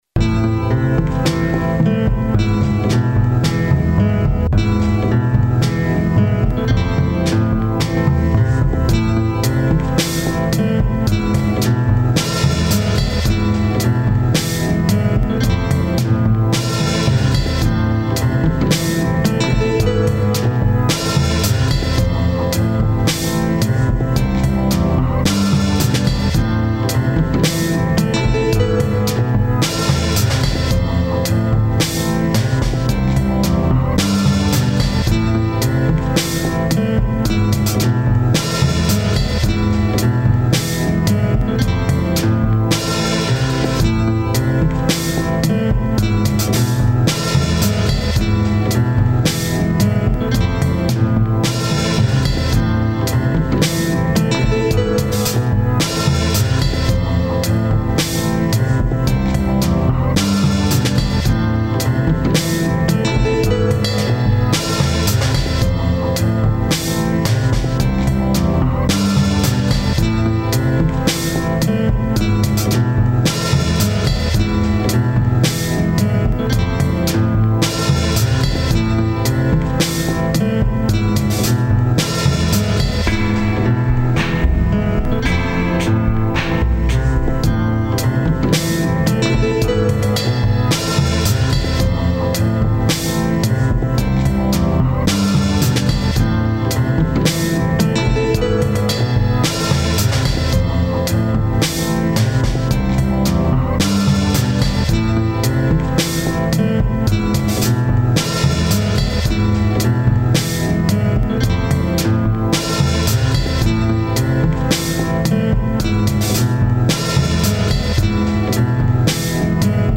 In this song I sampled music from the movie Santa Sangre and then added drums and keyboards. I played live drums, then cut them up, and sequenced them on Reason.
This sounds like my head is being surrounded by glowing lights.
The looping nature of this makes me imagine a really awesome side scrolling video game where you play a cowboy in a spaghetti western.